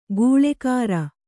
♪ gūḷekāra